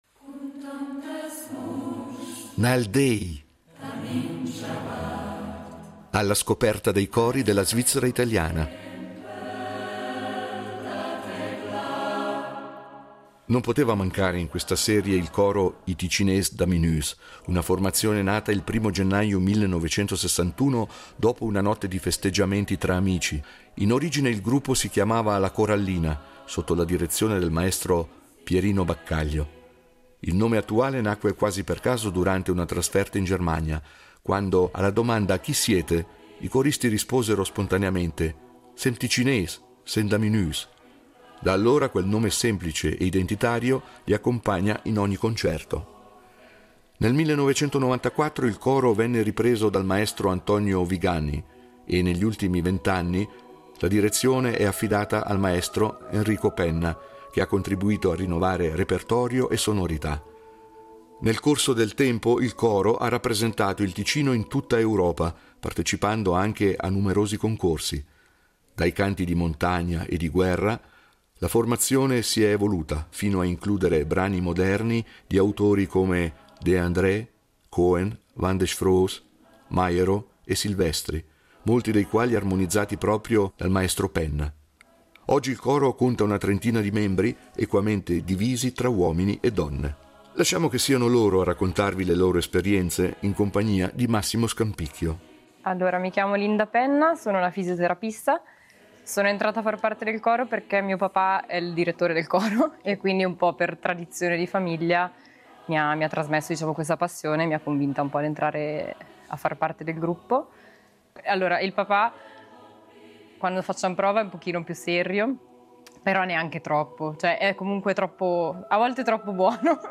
Nal déi, cori della svizzera italiana